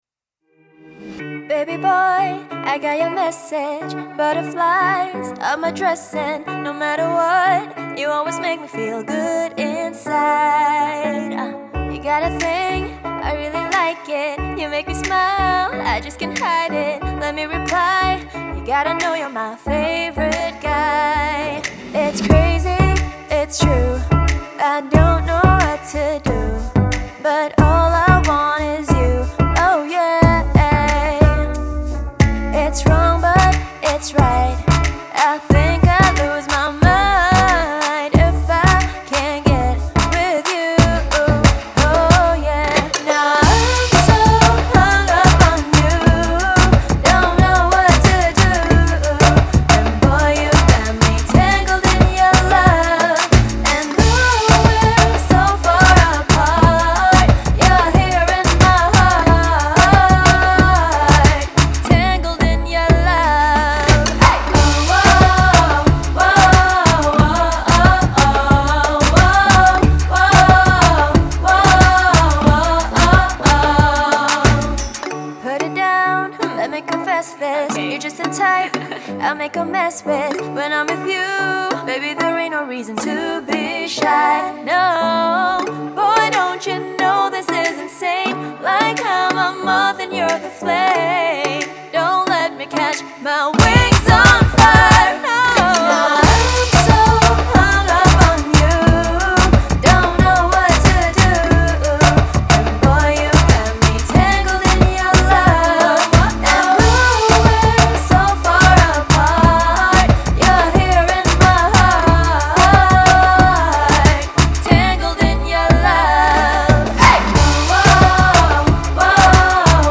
Genre: Pop.